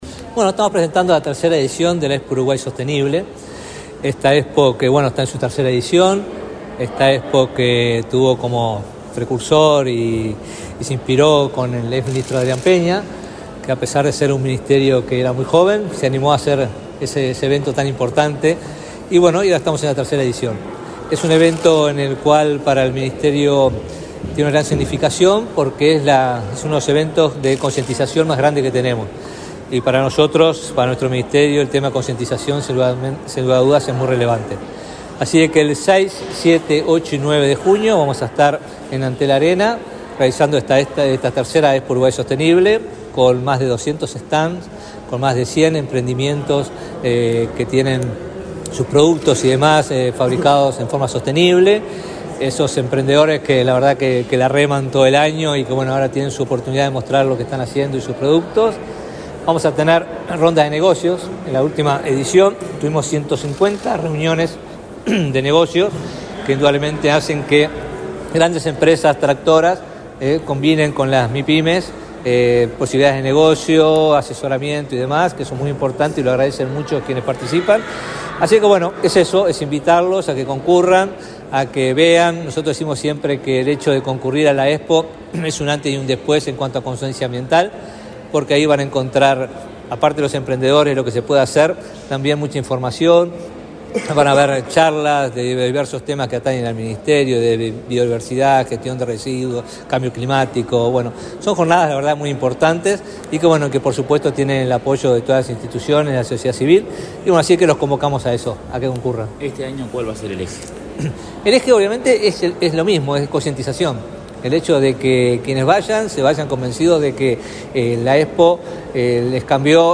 Declaraciones del ministro de Ambiente, Robert Bouvier
Este lunes 13, el ministro de Ambiente, Robert Bouvier, dialogó con la prensa en la Torre Ejecutiva, antes de participar en el lanzamiento de la 3.ª